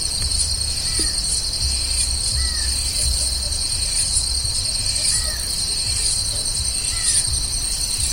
Variable Antshrike (Thamnophilus caerulescens)
Condition: Wild
Certainty: Recorded vocal